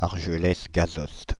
Argelès-Gazost (French pronunciation: [aʁʒəlɛs ɡazɔst]
Fr-Argelès-Gazost.ogg.mp3